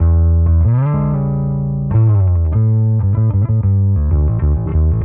Index of /musicradar/dusty-funk-samples/Bass/95bpm
DF_PegBass_95-E.wav